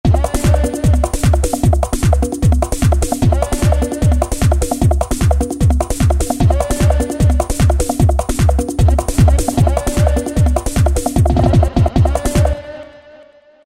007 Tribal House
Fast House loop, Tribal style.
007 Tribal House.mp3